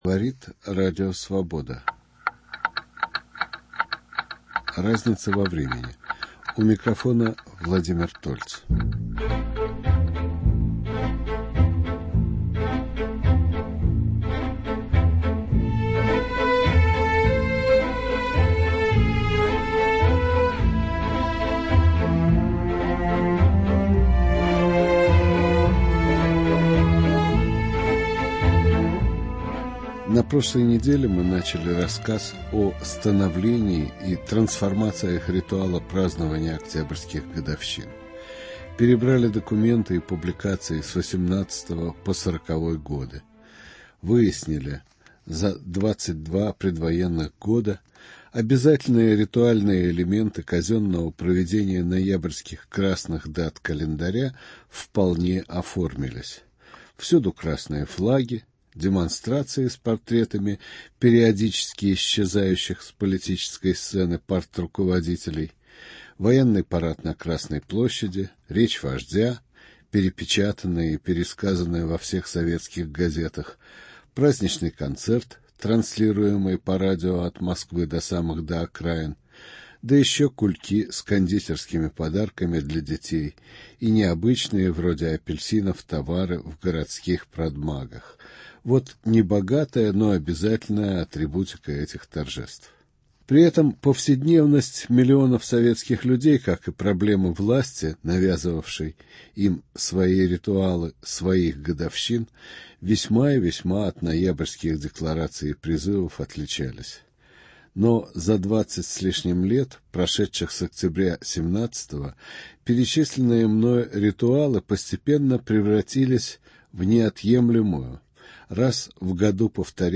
Вторая передача, посвященная истории изменений ритуала октябрьских годовщин. Гости программы - писатели Александр Кабаков, Сергей Каледин и Юрий Рост.